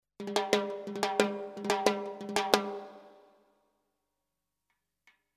Timbales fills in 90 bpm
The timbales are with light reverb and they are in 90 bpm.
This package contains real timbales fills playing a variety of fills in 90 bpm.
The free 5 samples are already with nice reverb.
The timbales were recorded using “ AKG C-12 ” mic. The timbales were recorded mono but the files are stereo for faster workflow.